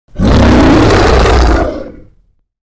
Monster-roar.mp3